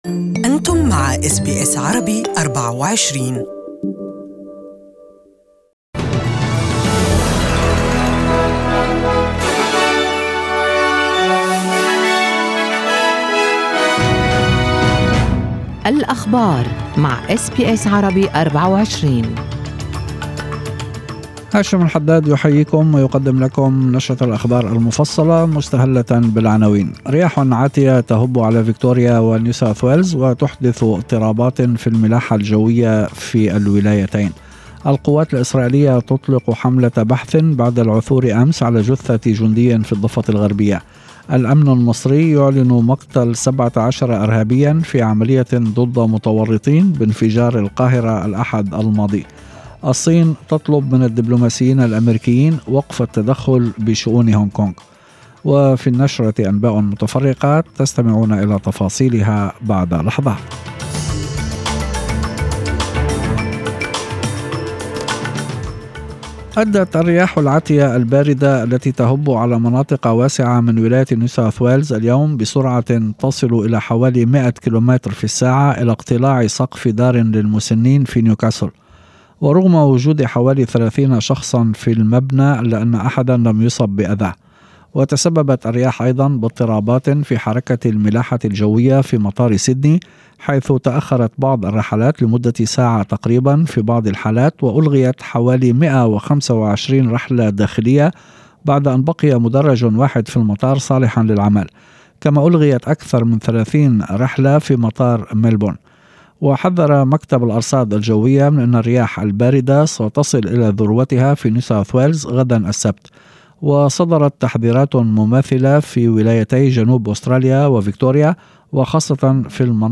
Evening News: Australia to stop exporting waste